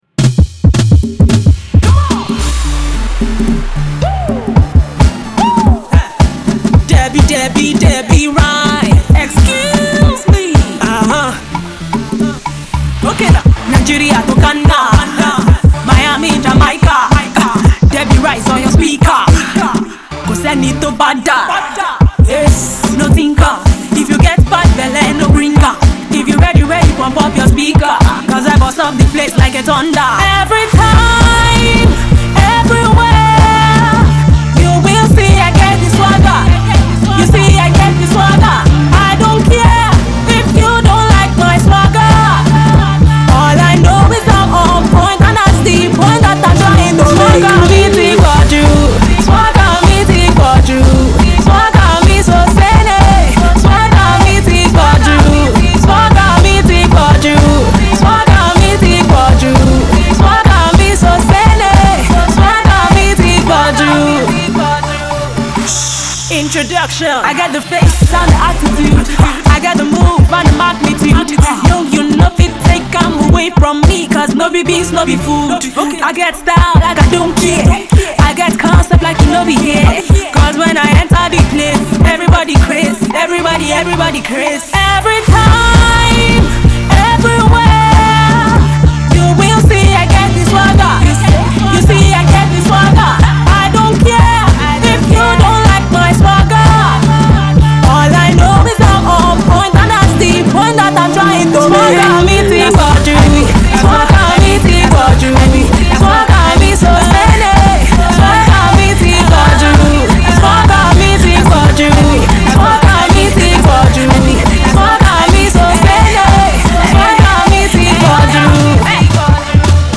goes the hip hop way